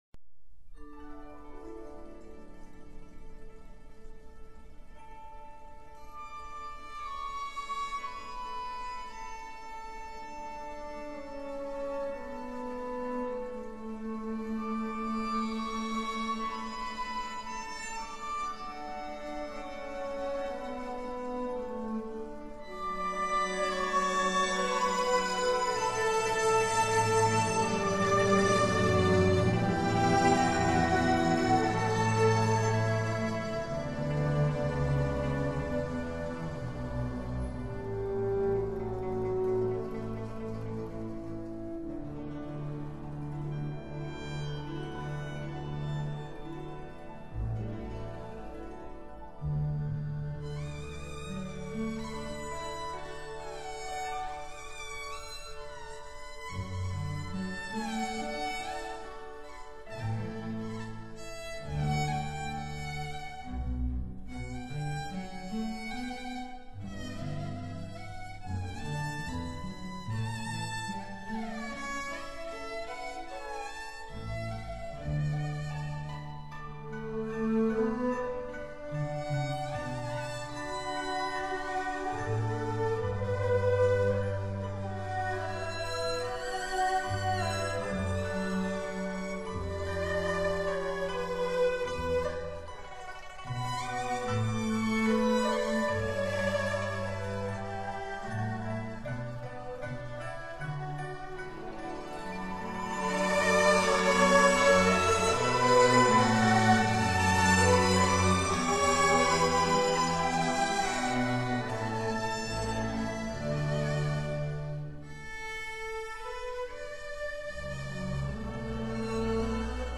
板胡独奏